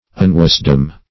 Unwisdom \Un*wis"dom\, n.